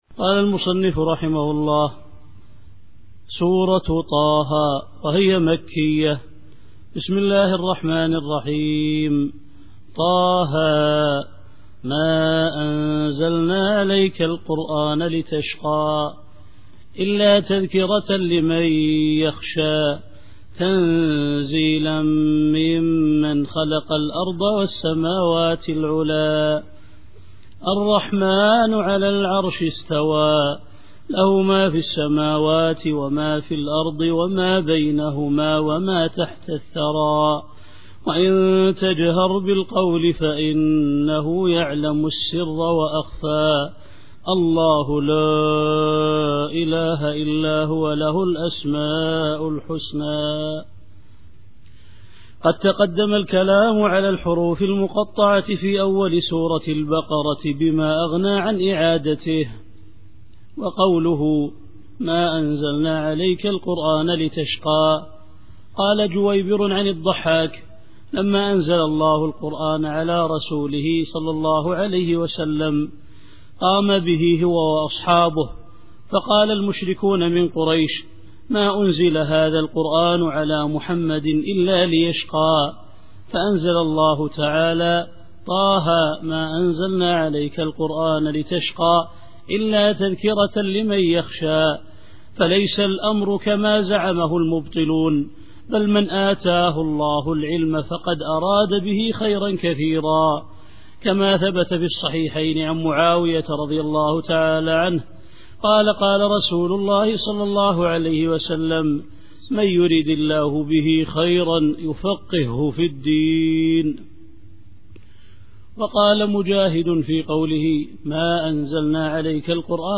التفسير الصوتي [طه / 1]